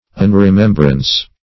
Search Result for " unremembrance" : The Collaborative International Dictionary of English v.0.48: Unremembrance \Un`re*mem"brance\, n. Want of remembrance; forgetfulness.